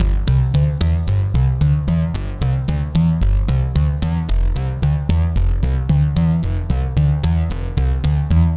C - Pulsing Bass02.wav